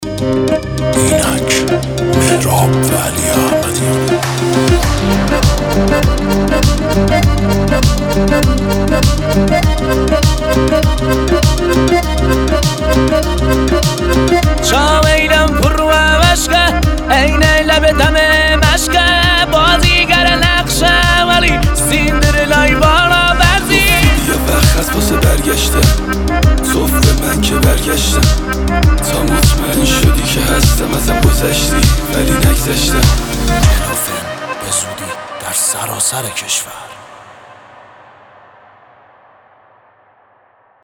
احساسی